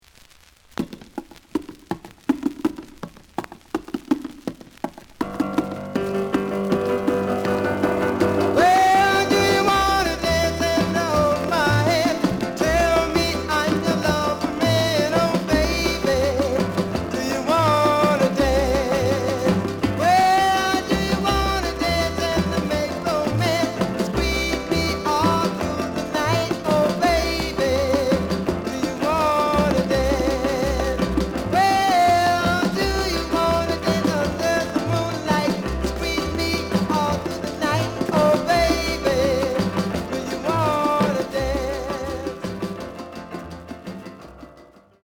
The audio sample is recorded from the actual item.
●Genre: Rhythm And Blues / Rock 'n' Roll
Some noise on A side.